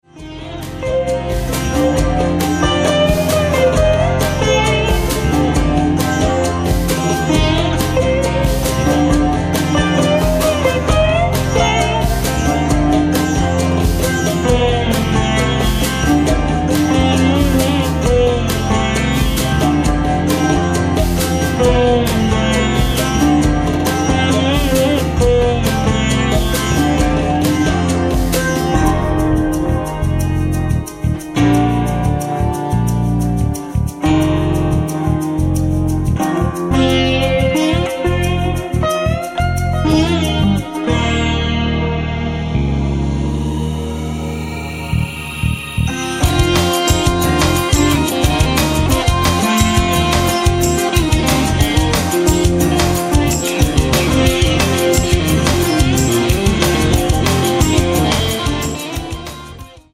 sitar, guitar, vocals
bass
drums